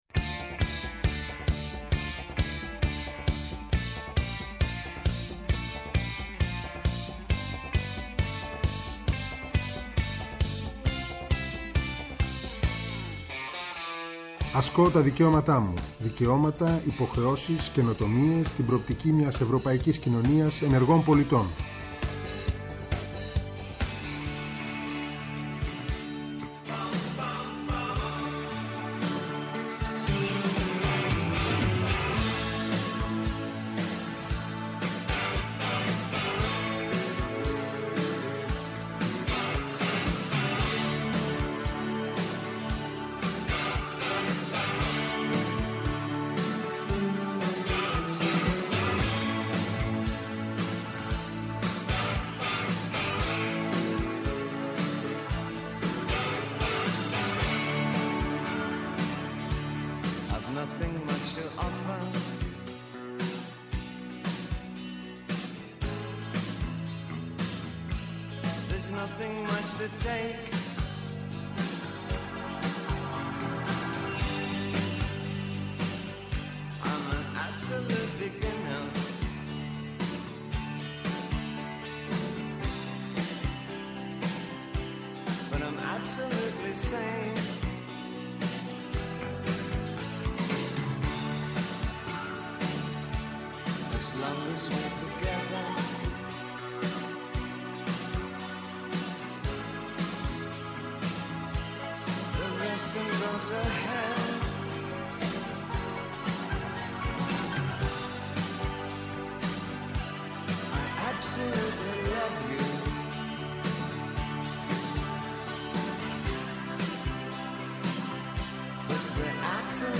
Αυτό το Σάββατο καλεσμένοι είναι :